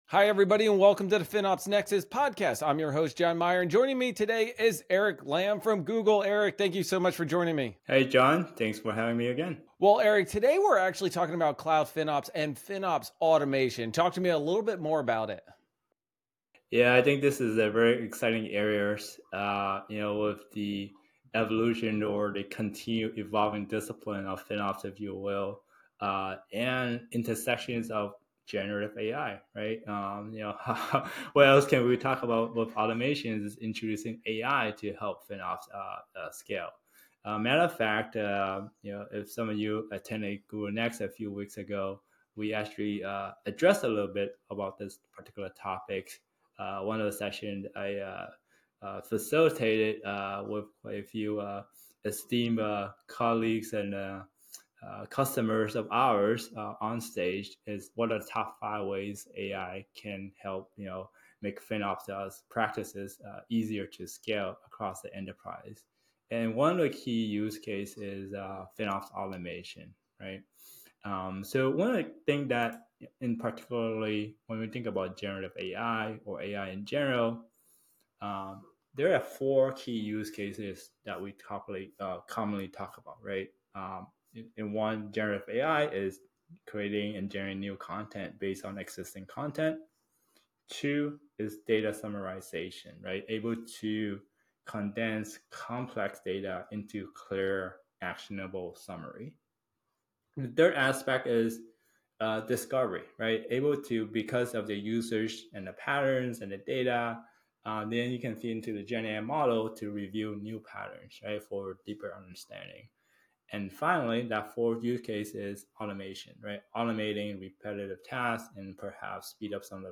Learn how Google is integrating AI to streamline FinOps processes, enhance data insights, and drive business value. Discover the key use cases of AI in FinOps, from generating cost reports to automating repetitive tasks. Don't miss this insightful conversation on the future of FinOps and AI.